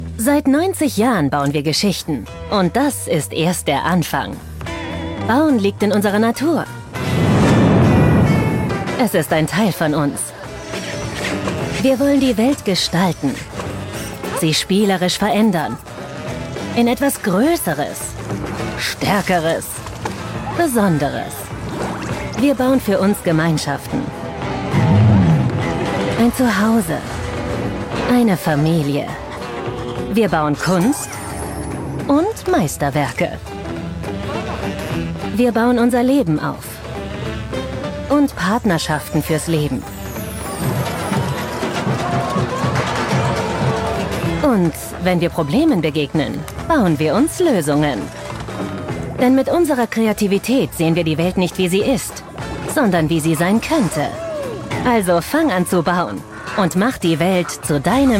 sehr variabel
Commercial (Werbung)